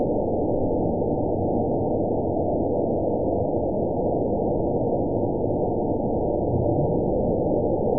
event 920184 date 03/03/24 time 22:25:12 GMT (1 year, 2 months ago) score 7.16 location TSS-AB09 detected by nrw target species NRW annotations +NRW Spectrogram: Frequency (kHz) vs. Time (s) audio not available .wav